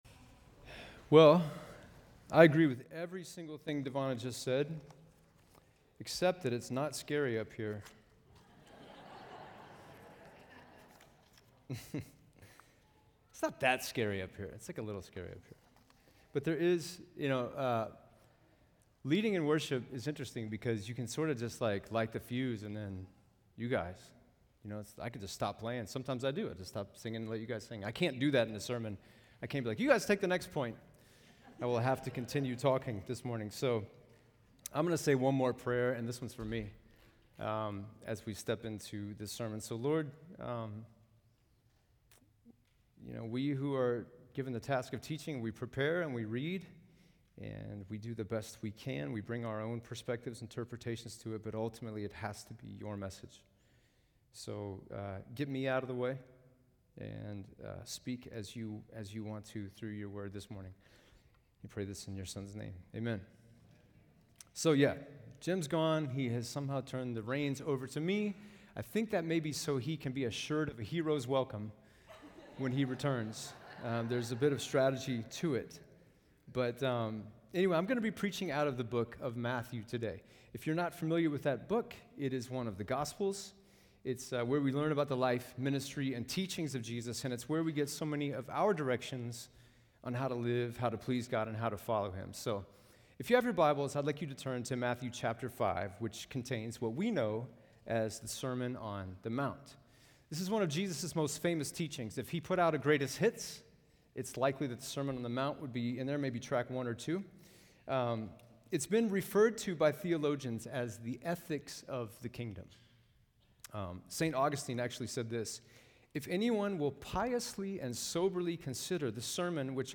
A special message